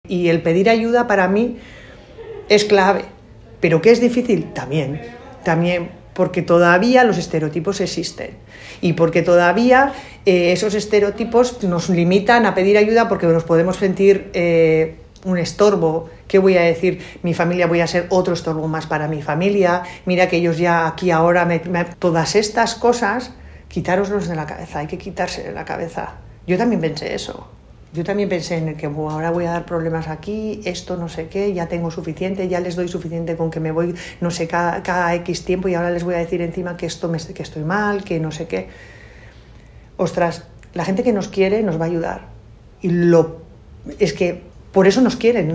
dijo con rotundidad animando siempre a buscar apoyo en las situaciones de salud mental formato MP3 audio(0,87 MB).